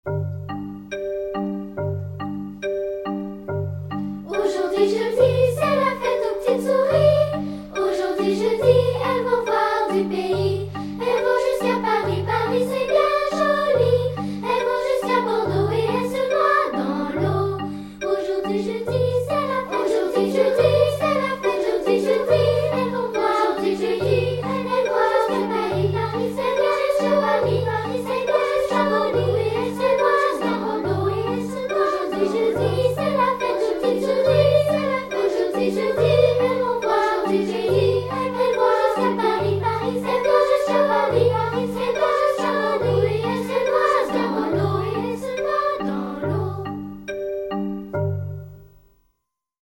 Musique vocale